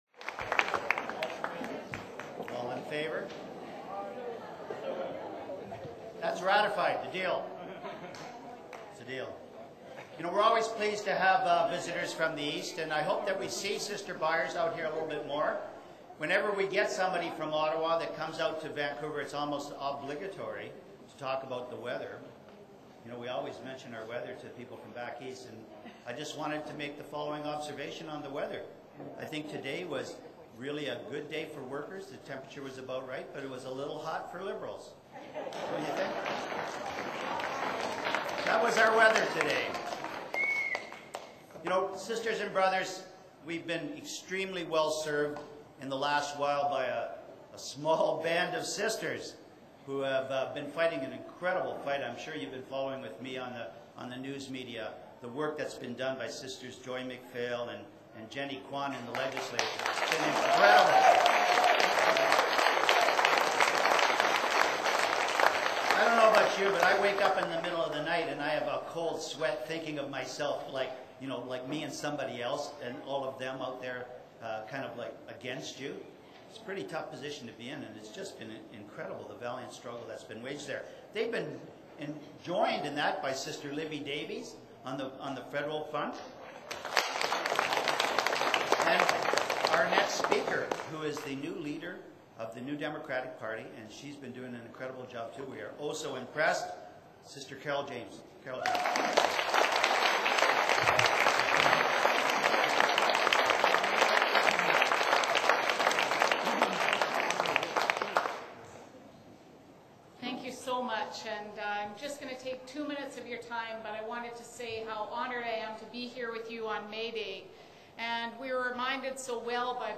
MAYDAY DINNER: at the Maritime Labour Centre.
Mayday dinner- Carole James, leader BC NDP RT: 6:14